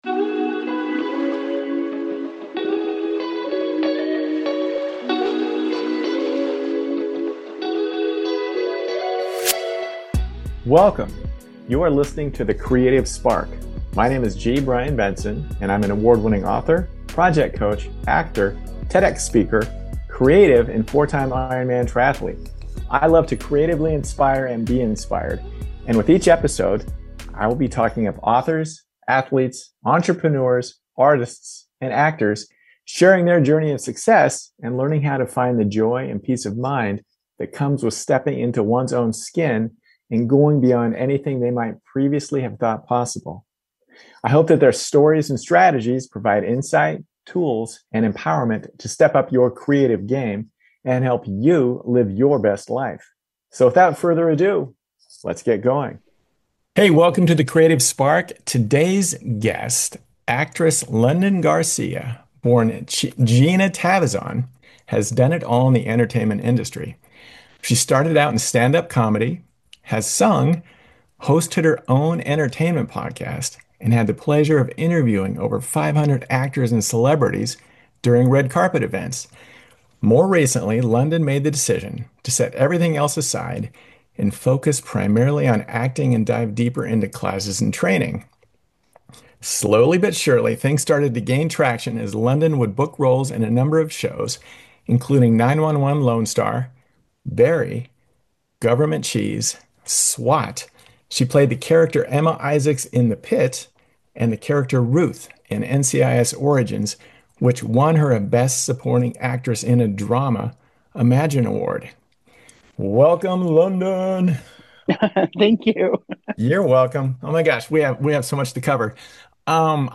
You don't want to miss any of today's interesting and inspiring chat!